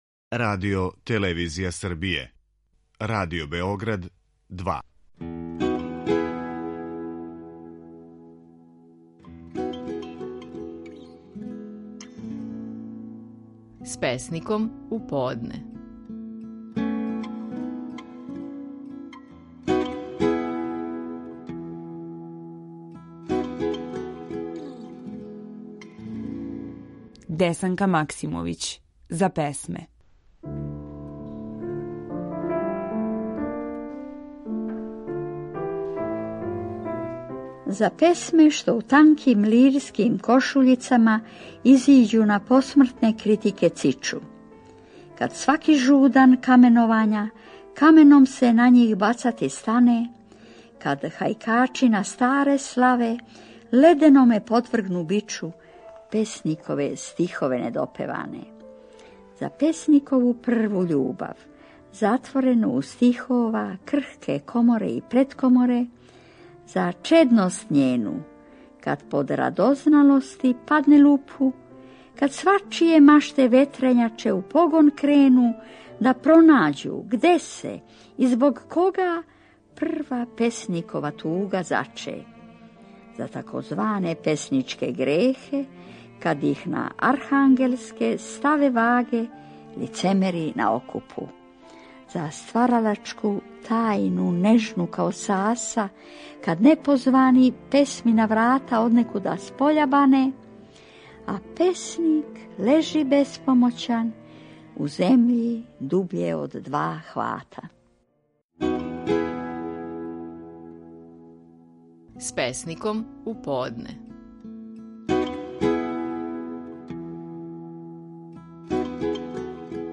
Стихови наших најпознатијих песника, у интерпретацији аутора.
Десанка Максимовић говорила је: „За песме".